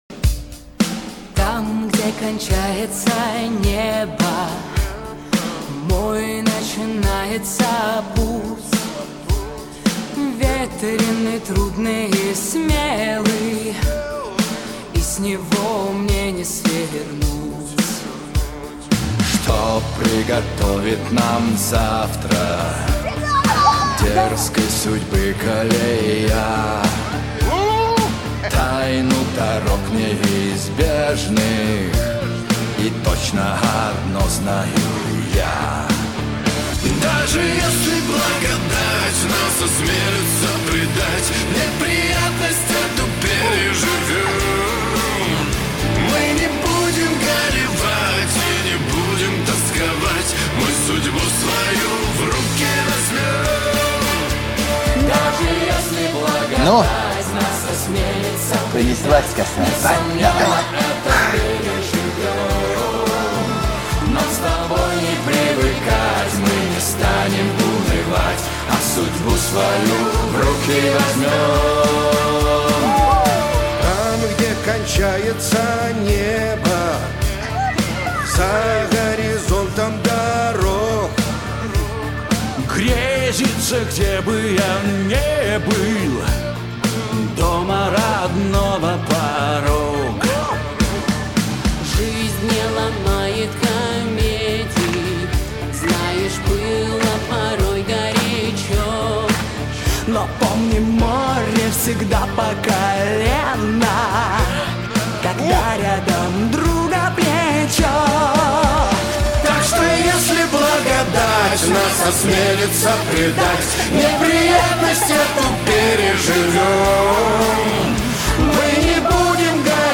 Качество: 320 kbps, stereo
Саундтреки